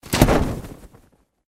target_chute.ogg